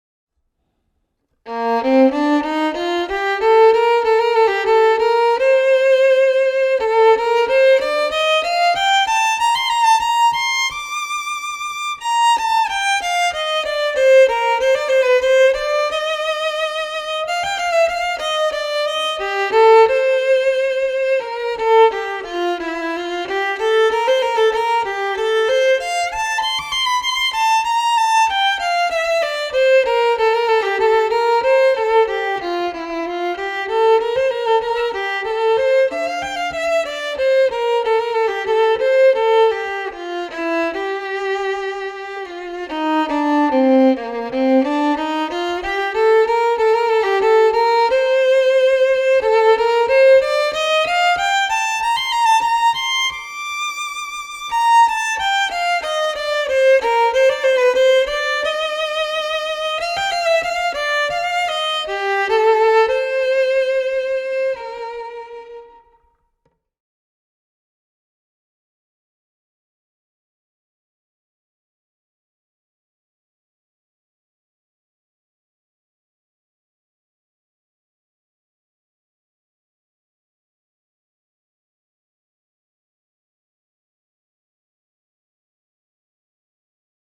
Una selección de estudios del libro 60 estudios para violín op. 45 del violinista y pedagogo alemán F. Wolfhart, en la edición de Frigyes Sándor.